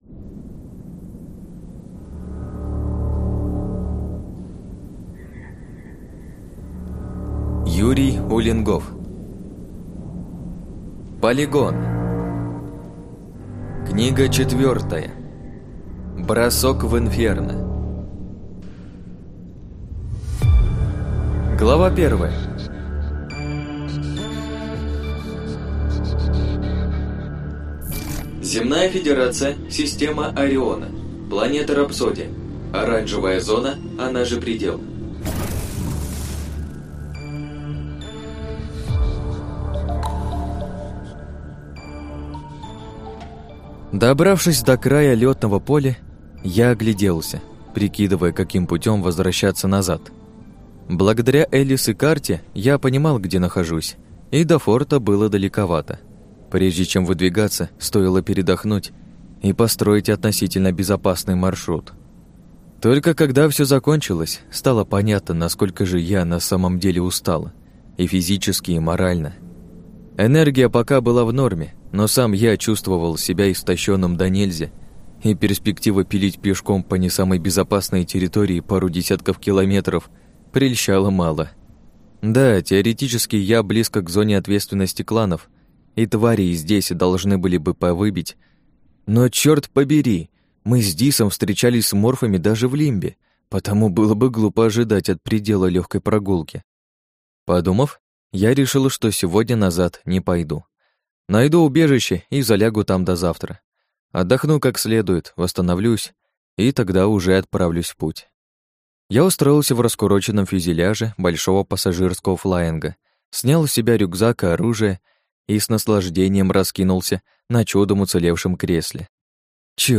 Аудиокнига Полигон-4. Бросок в Инферно | Библиотека аудиокниг